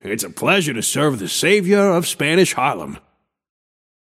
Shopkeeper voice line - It’s a pleasure to serve the savior of Spanish Harlem.
Shopkeeper_hotdog_t4_ivy_01.mp3